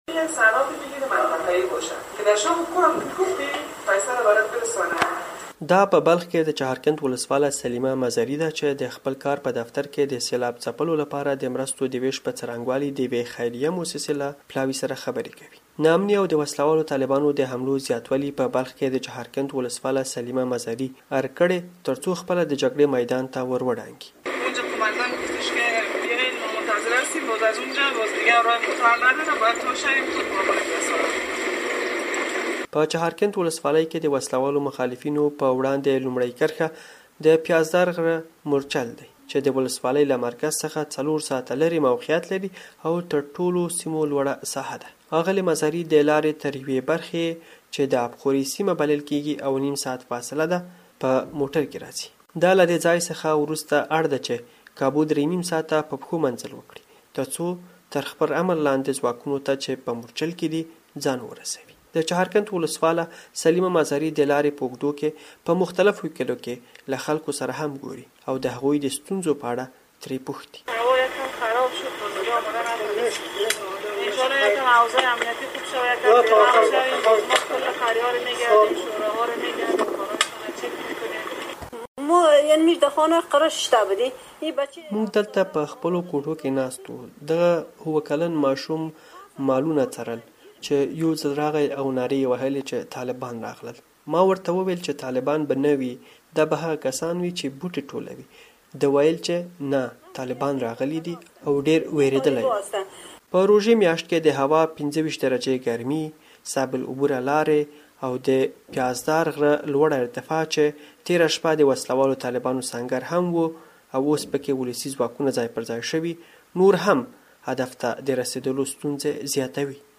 بلخ راپور